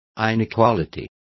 Complete with pronunciation of the translation of inequality.